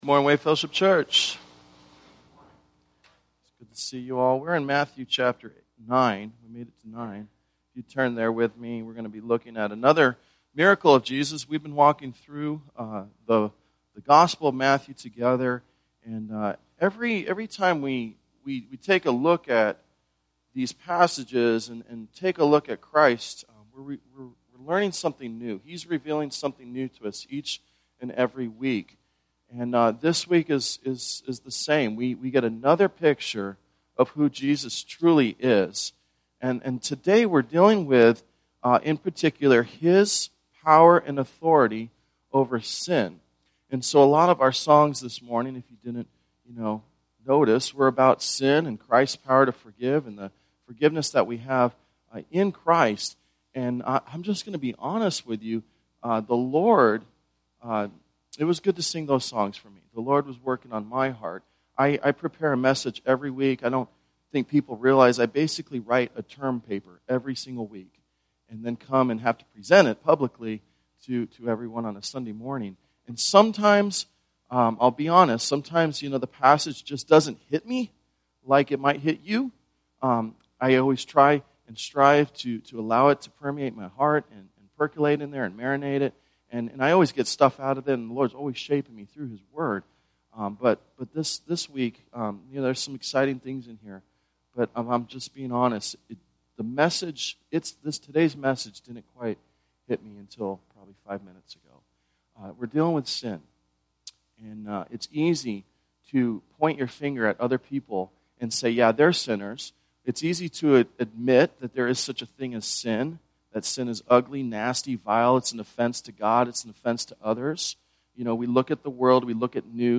Sunday Worship
Tagged with Sunday Sermons